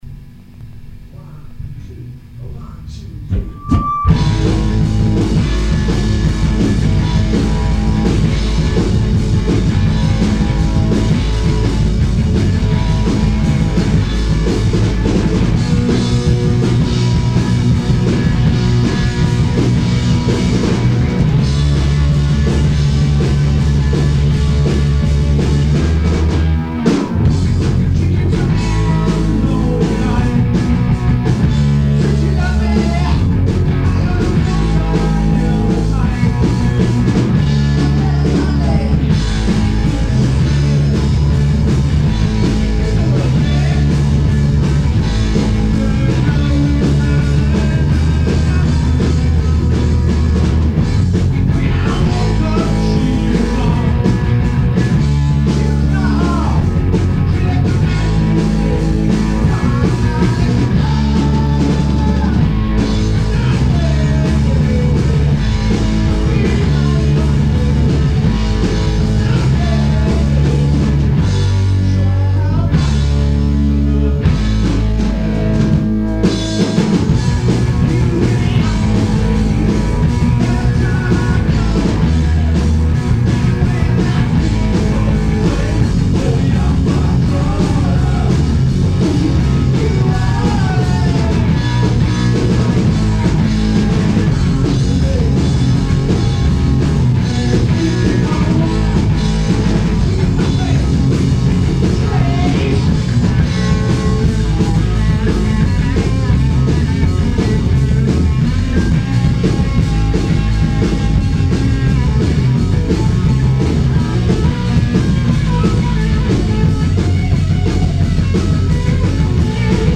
Shepperton Room 60